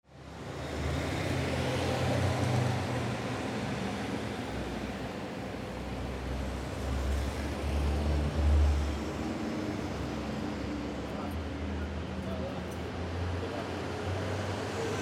Background Sound Effects, Transportation Sound Effects
traffic_03-1-sample.mp3